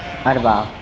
speech
speech-commands